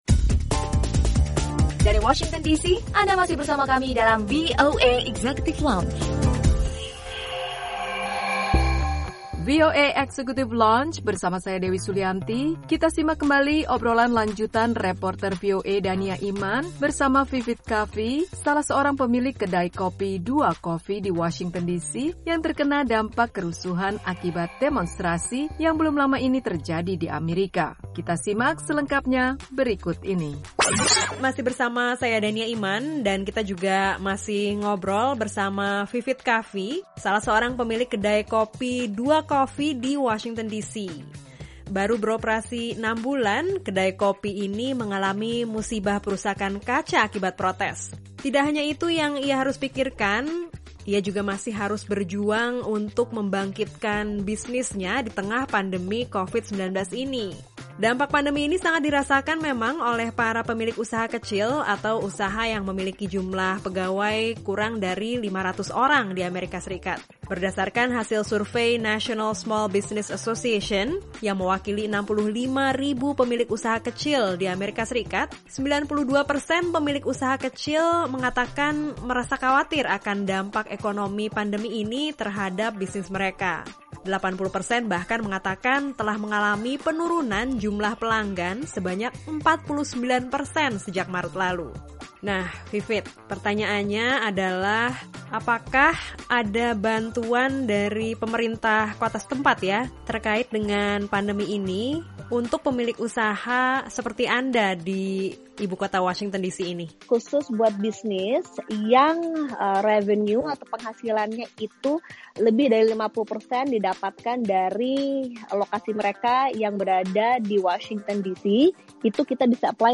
Obrolan lanjutan